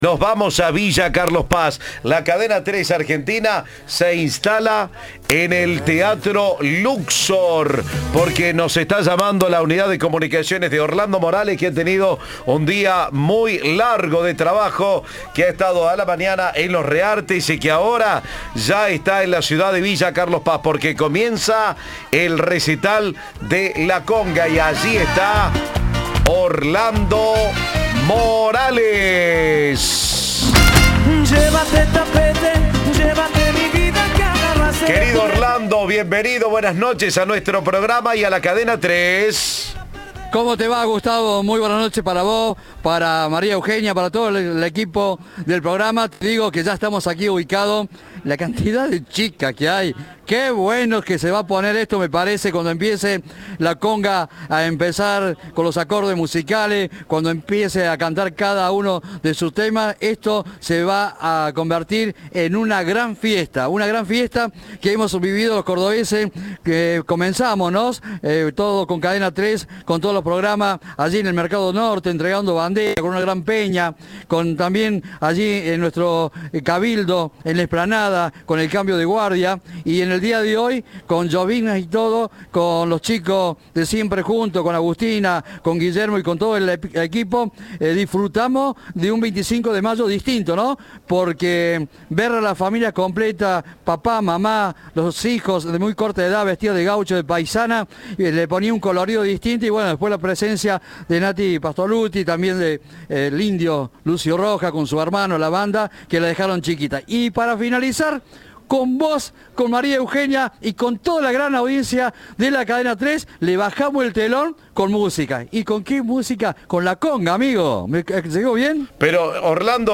El grupo del momento, liderado por Pablo Tamagnini, Diego Granadé y Nelson Aguirre, dialogó con Cadena 3 antes del primero de sus dos shows en ese clásico recinto.
Audio. La Konga en el teatro Luxor de Villa Carlos Paz
El show fue trasmitido por La Popu y antes de la primera presentación, Cadena 3 dialogó con los integrantes de la banda, que celebraron el buen momento que viven.